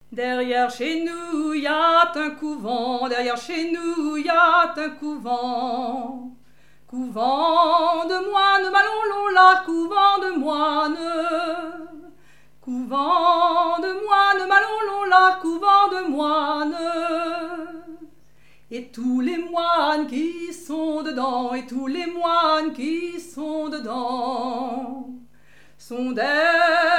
danse : ronde
Genre laisse
répertoire de chansons
Pièce musicale inédite